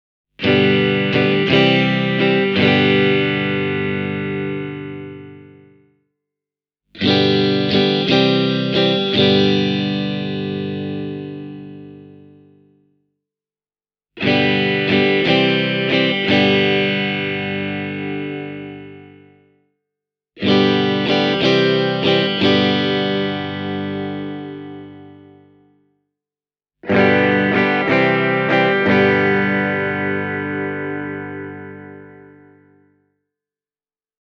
The Three-Tone Sunburst version gives you the tones you’d expect from a factory-modded vintage S-type.
Thanks to the vintage-style single coils – as well as the PAF-inspired bridge humbucker – you get a lot of clarity, dynamics and spank.